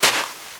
High Quality Footsteps
STEPS Sand, Walk 25.wav